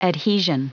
Prononciation du mot adhesion en anglais (fichier audio)
Prononciation du mot : adhesion